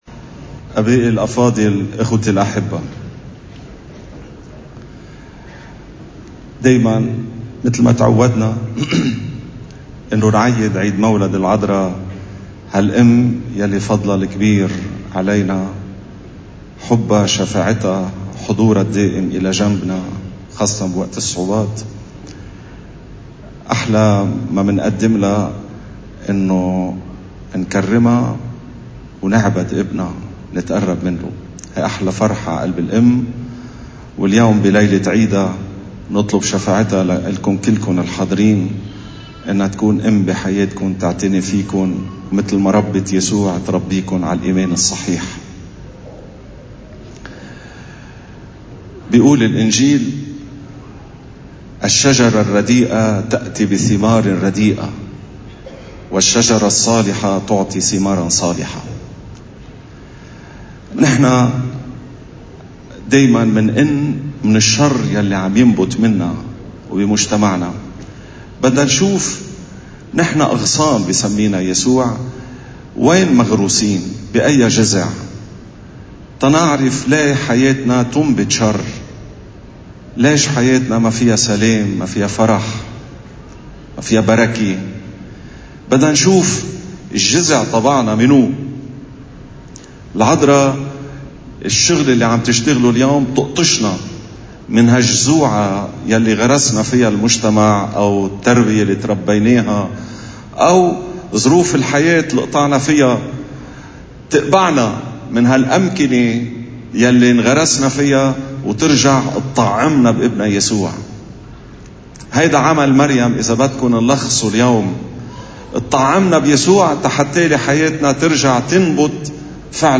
قداس السلام، في دير القديسة تريزيا الطفل يسوع – سهيلة